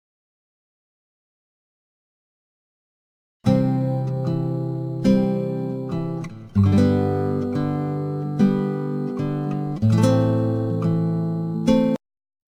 version réduite et compressée pour le Web